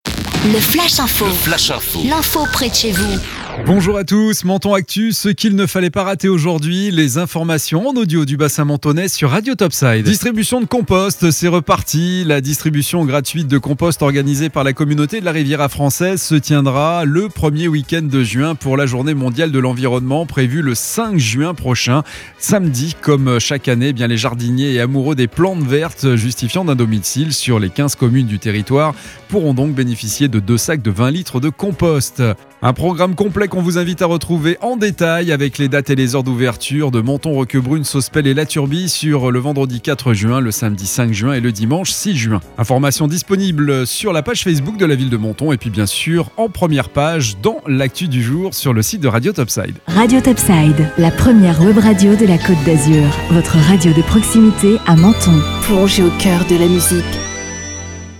Menton Actu - Le flash info du vendredi 7 mai 2021